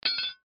音效
修复失败.mp3